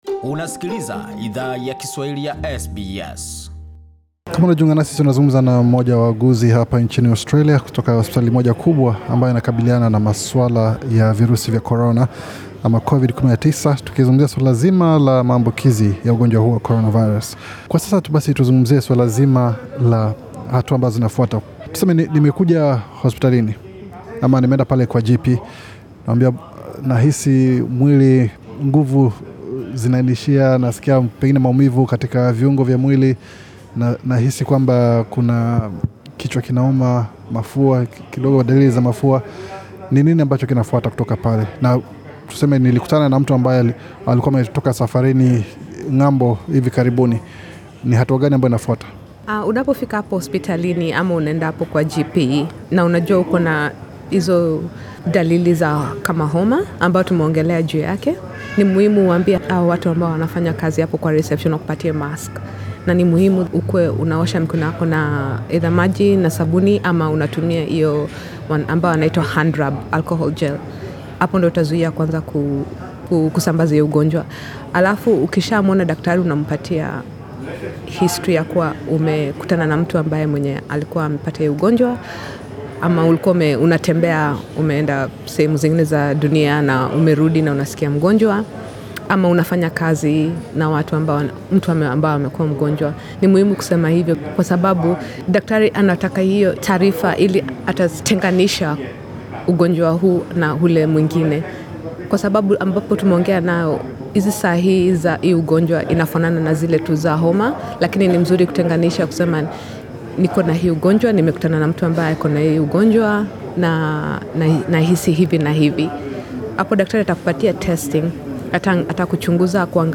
Katika sehemu ya pili yamahojiano haya yakujua zaidi kuhusu kiini cha virusi hivi na jinsi yakupata kinga, Idhaa ya Kiswahili ya SBS ilizungumza na mtaalam wa magonjwa yakuambukiza, anaye fanya kazi katika moja ya hospitali kubwa nchini Australia, ambako waathiriwa wa virusi hivyo wanapokea matibabu. Mtaalam huyo alifafanua hatua ambazo mtu anastahili chukua, anapo jihisi anavirusi vya coronavirus, pamoja na mbinu zakujikinga dhidi ya maambukizi ya virusi hivyo vya coronavirus.